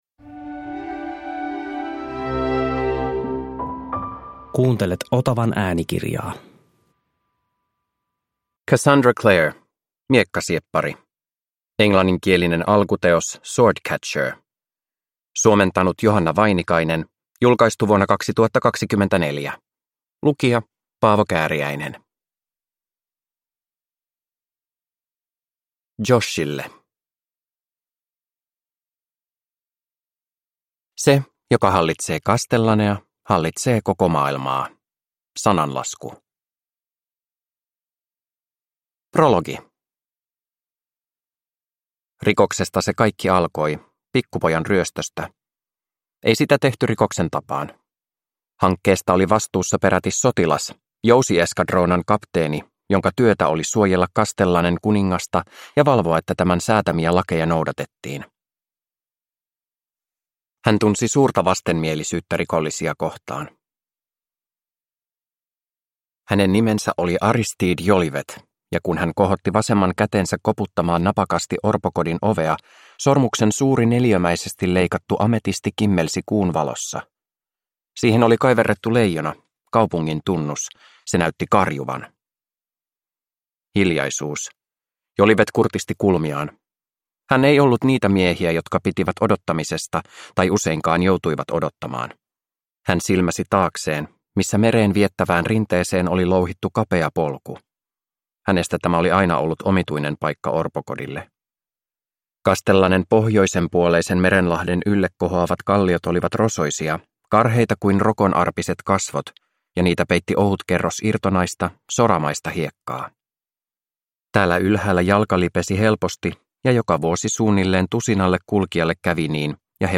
Miekkasieppari – Ljudbok